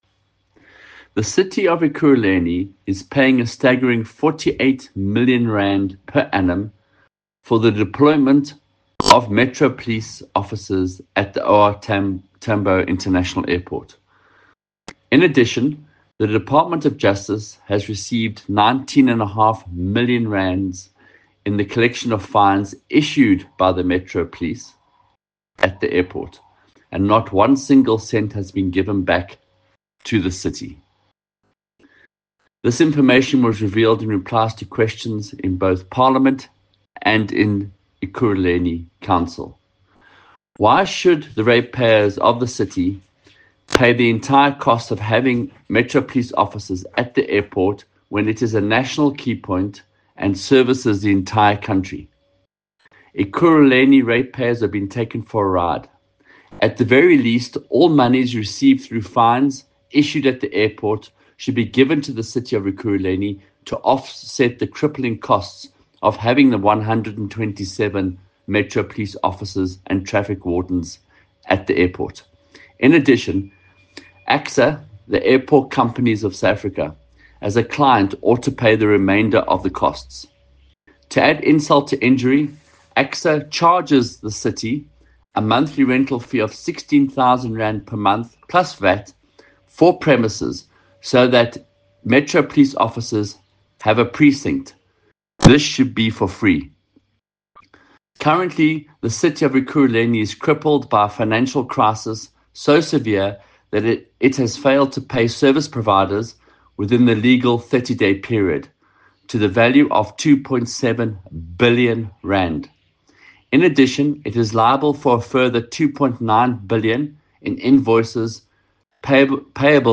Note to Editors: Please find an English soundbite by Michael Waters MPL